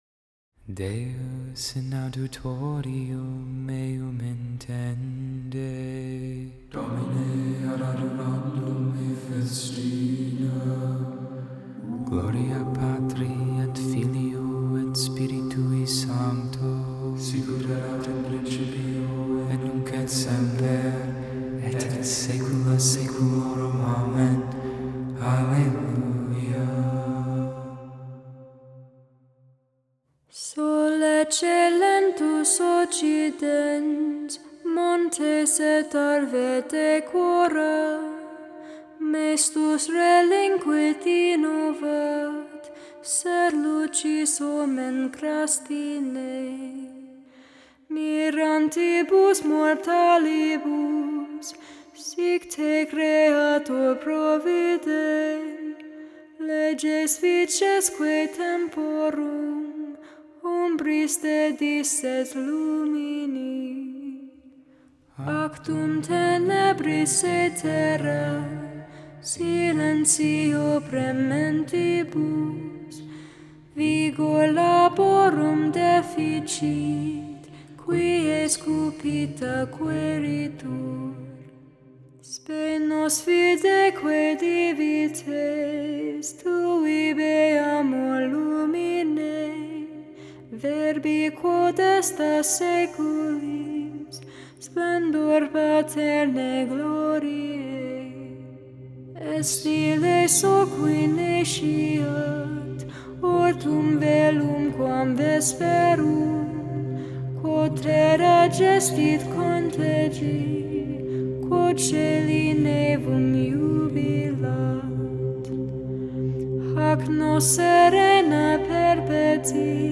Hymn: Sol, ecce, lentus Psalm 62 (Tone 1) Psalm 67 (Tone 2) Canticle: Colossians 1:12-20 READING: 1 Peter 5:5b-7 Responsory: Keep us, O Lord, as the apple of your eye.